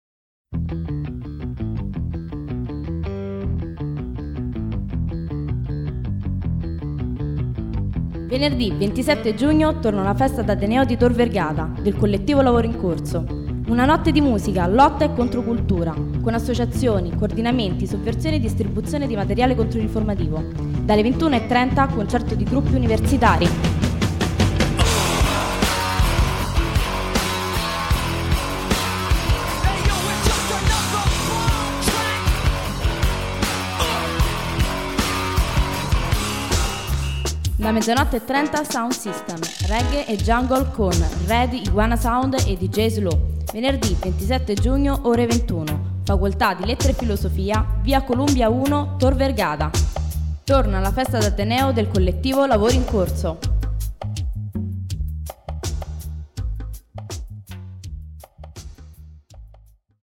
Spot_per_radio.mp3